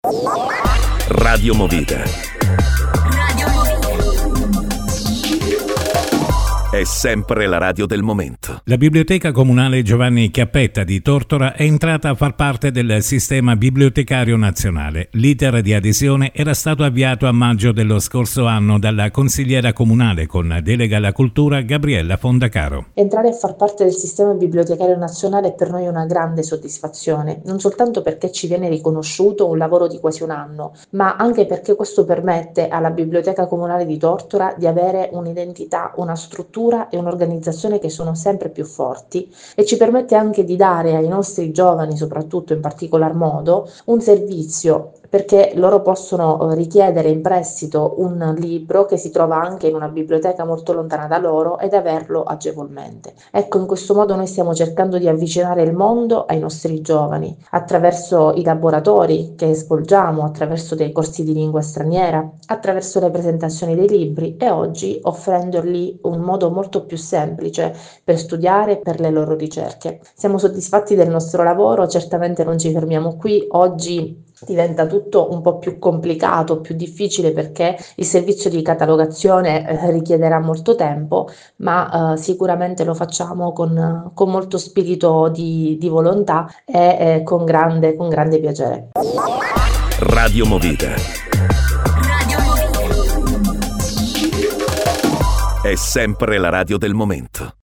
INTERVISTA A GABRIELLA FONDACARO